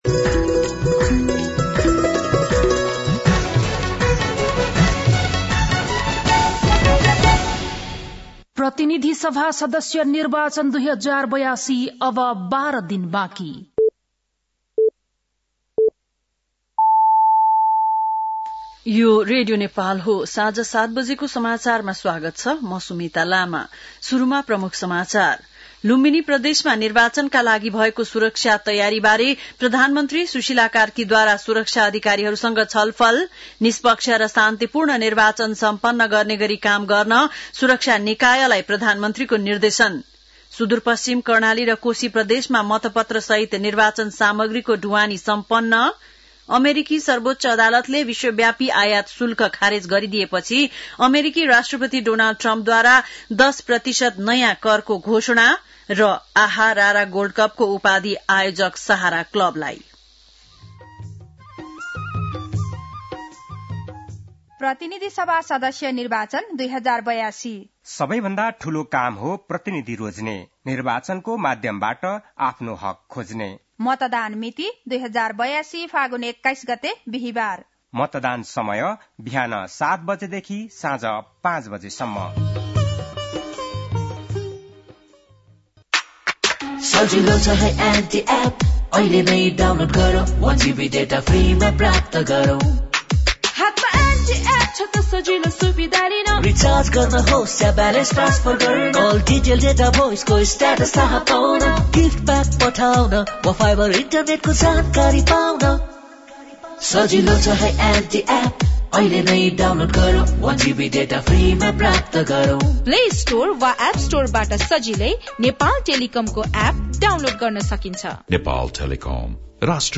बेलुकी ७ बजेको नेपाली समाचार : ९ फागुन , २०८२